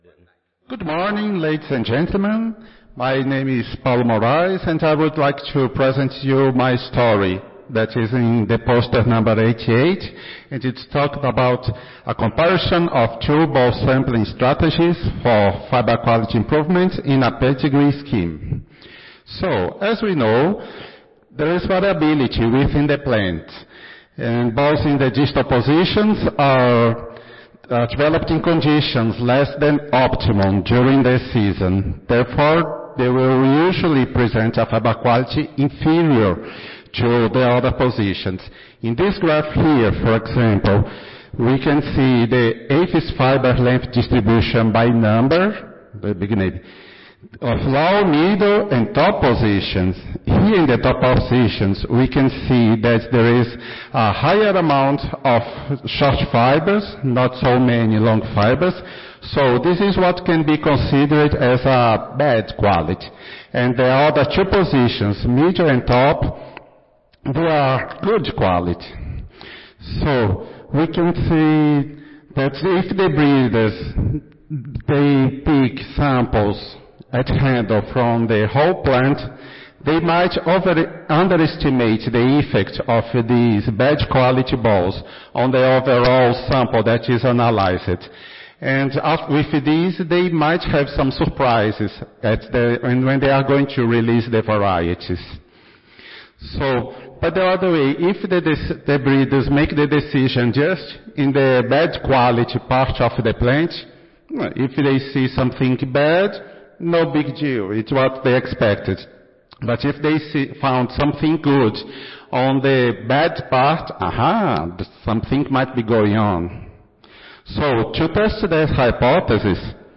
Cotton Improvement - Lightning Talk Student Competition
Audio File Recorded Presentation